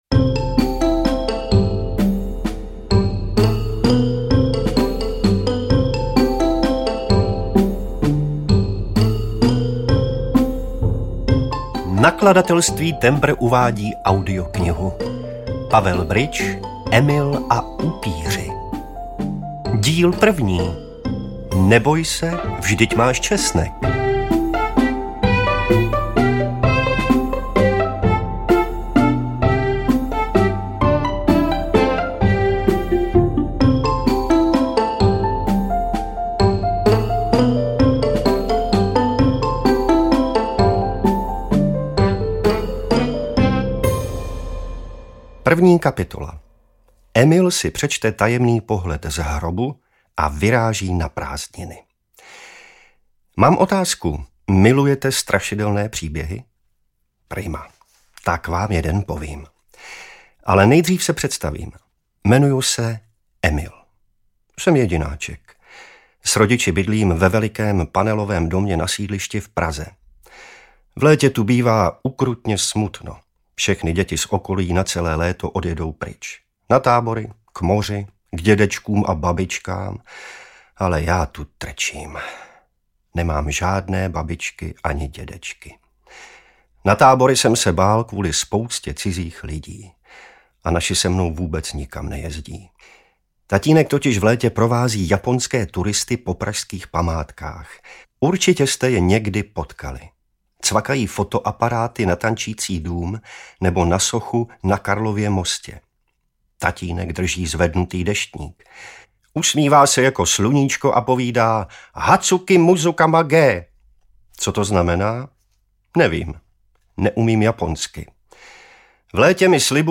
Emil a upíři 1-5 audiokniha
Ukázka z knihy